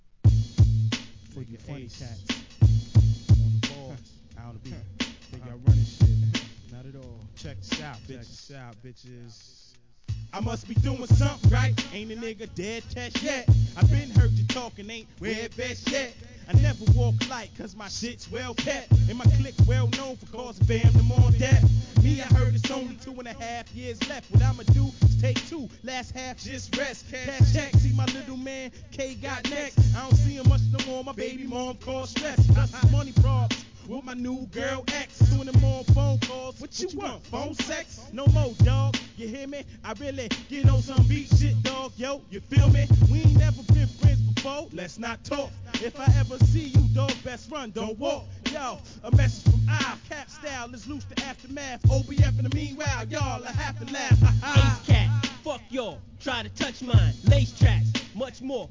HIP HOP/R&B
LATE '90s NEW YORK FREESTYLE!!